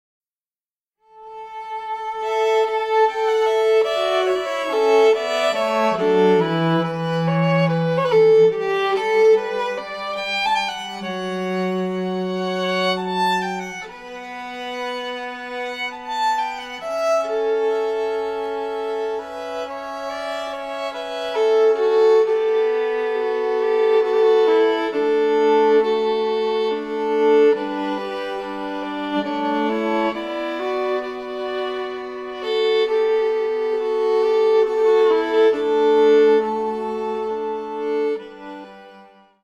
Bass
Fiddle
Cello
guitar